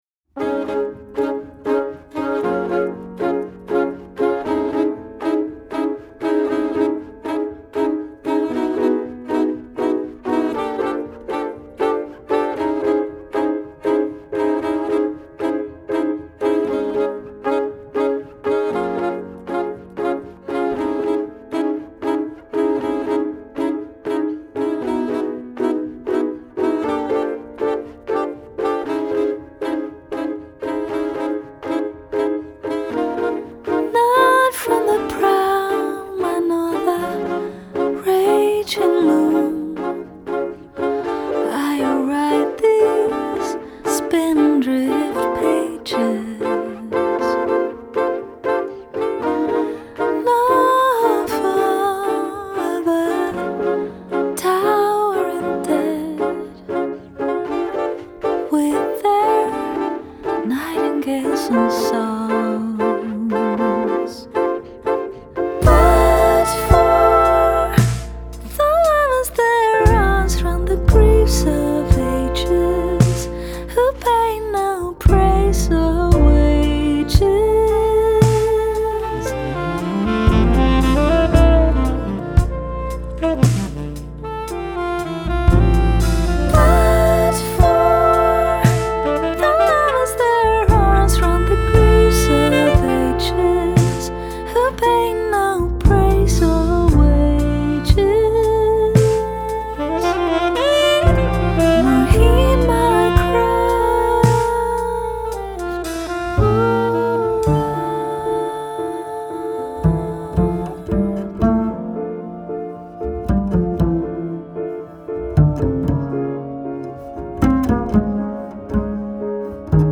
vocals
piano
saxophone